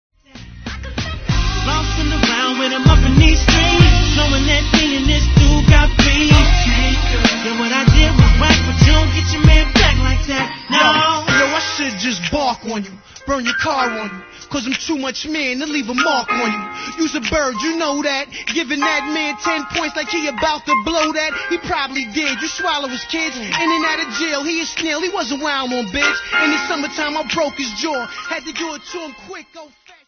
MAIN ( CLEAN )